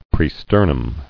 [pre·ster·num]